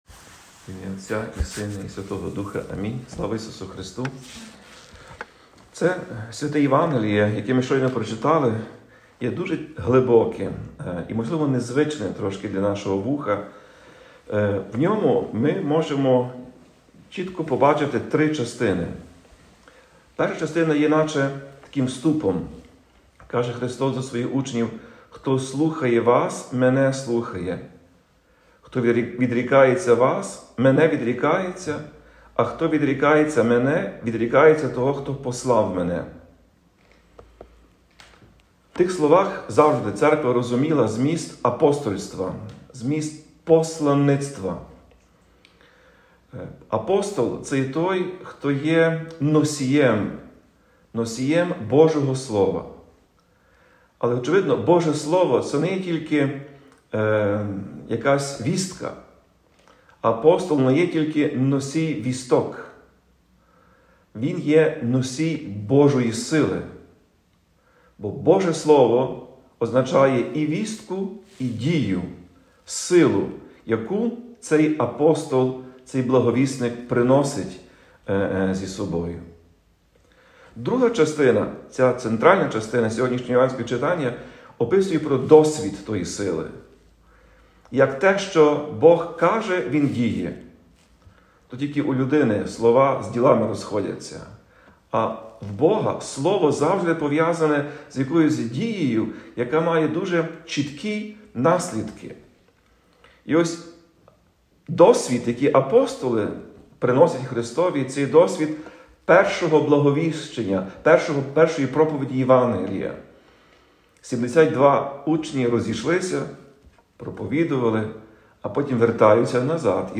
У проповіді Блаженніший Святослав роздумував над євангельським уривком, який, за його словами, може звучати незвично для людського вуха, але відкриває нам три частини.
Проповідь Блаженнішого Святослава у свято Архистратига Михаїла